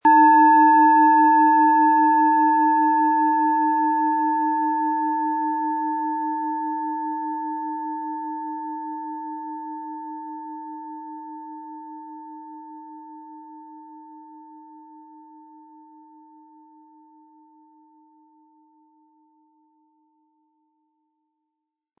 Planetenschale® Sinnlich Sein und Fühlen & Hemmungen verlieren mit Eros, Ø 12,3 cm, 180-260 Gramm inkl. Klöppel
Planetenton 1
Im Sound-Player - Jetzt reinhören können Sie den Original-Ton genau dieser Schale anhören.
Aber dann würde der ungewöhnliche Ton und das einzigartige, bewegende Schwingen der traditionellen Herstellung fehlen.
SchalenformBihar
MaterialBronze